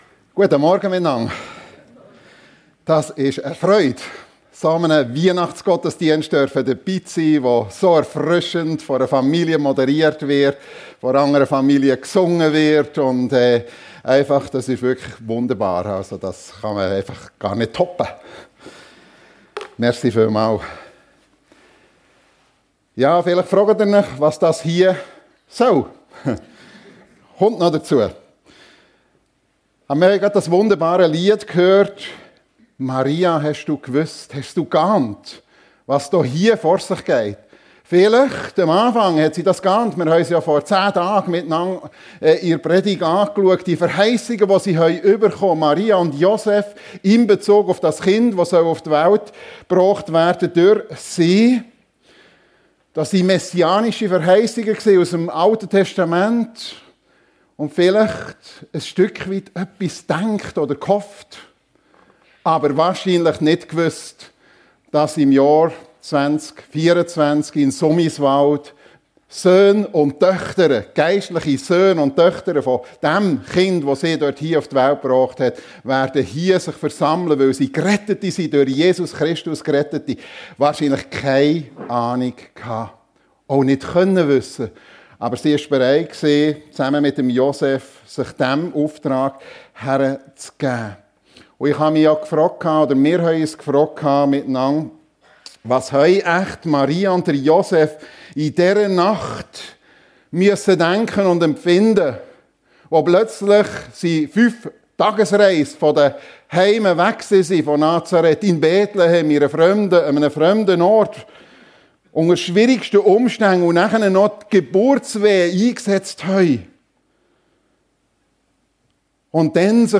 Weihnachten ~ FEG Sumiswald - Predigten Podcast